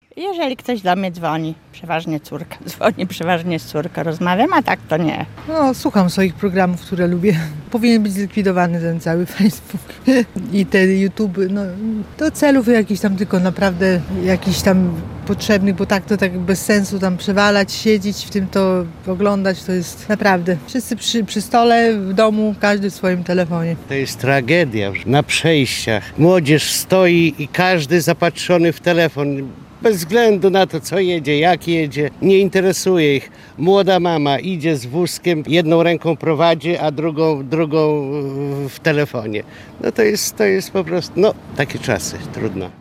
Łomżyniacy uważają, że smartfony ułatwiają codzienne życie, ale także mogą być dla nas zagrożeniem: